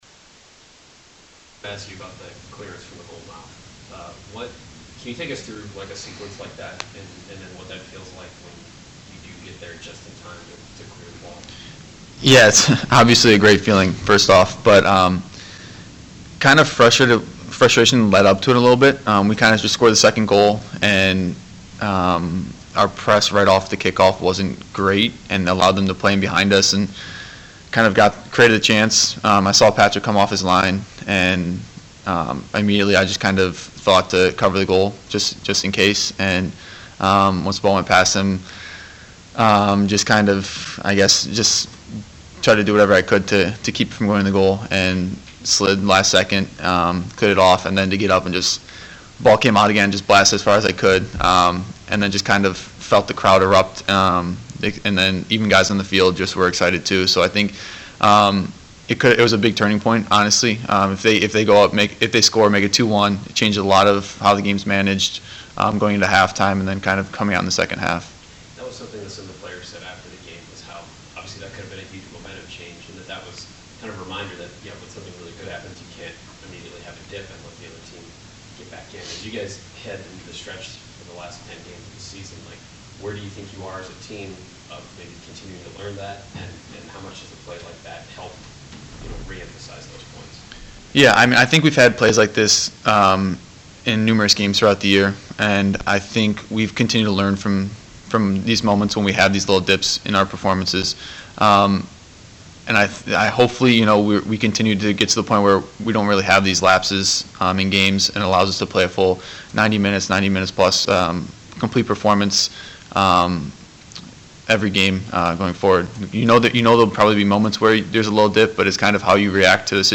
meets the Media for a preview